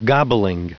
Prononciation du mot gobbling en anglais (fichier audio)